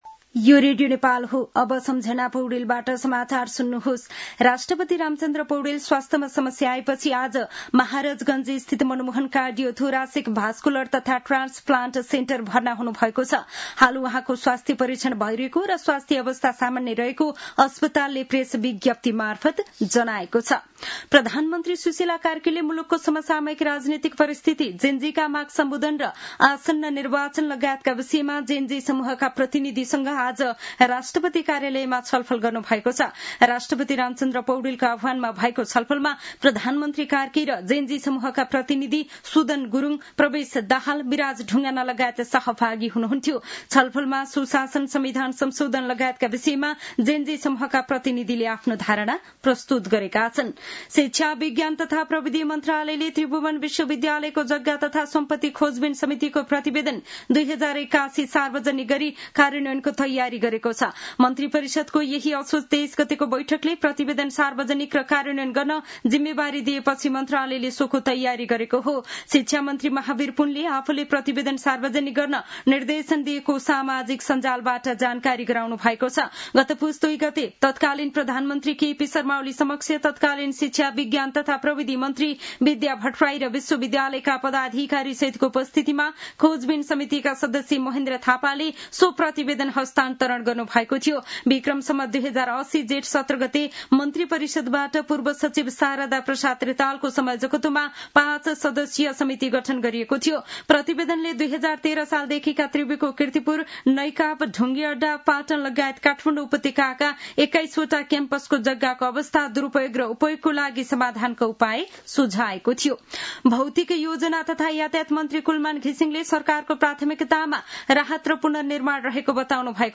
साँझ ५ बजेको नेपाली समाचार : २५ असोज , २०८२